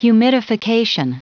Prononciation du mot humidification en anglais (fichier audio)
humidification.wav